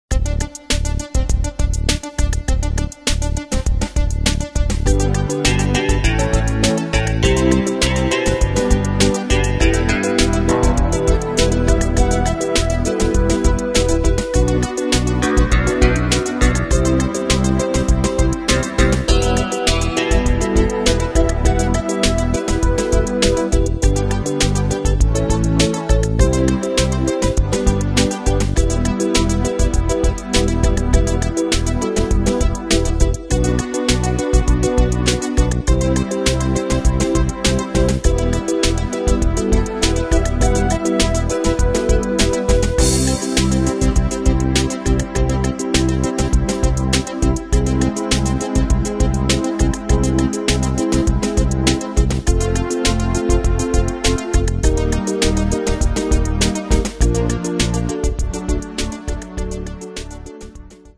Pro Backing Tracks